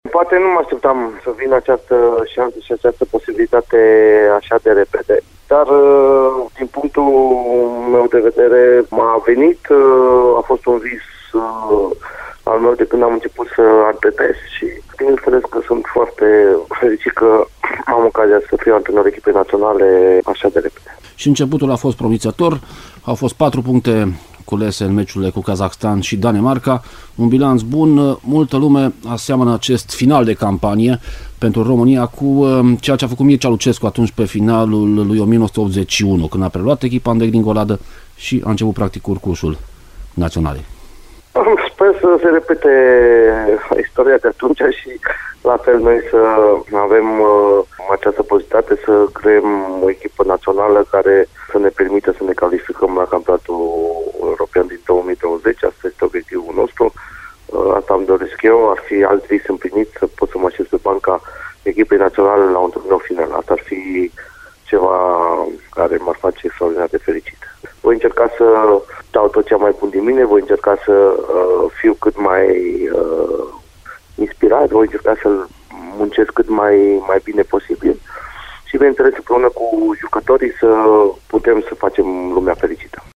Antrenorul care luna viitoare va împlini 42 de ani pregătește meciurile ce vor încheia anul 2017, amicalele cu Turcia și Olanda, înaintea cărora a stat de vorbă cu Radio Timișoara despre planurile și cariera sa.